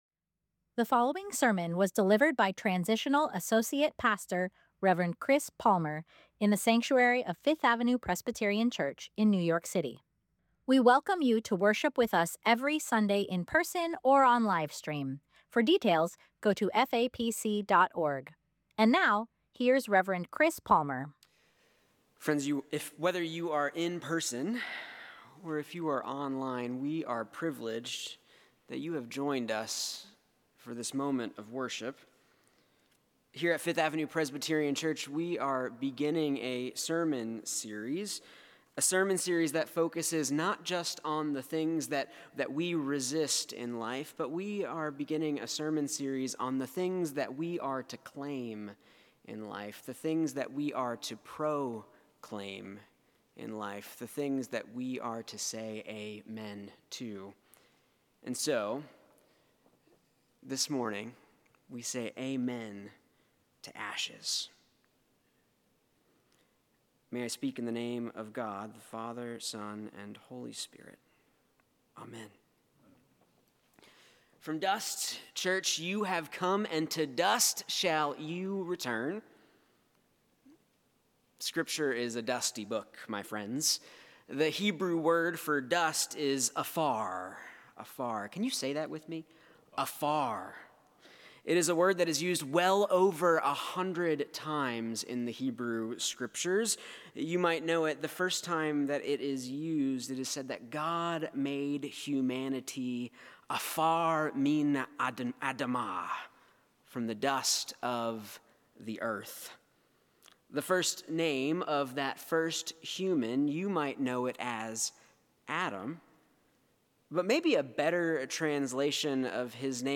Sermon: “Say Amen to Ashes” Scripture: Psalm 103:8-18 Download sermon audio Order of Worship T he Lord is merciful and gracious, slow to anger and abounding in steadfast love.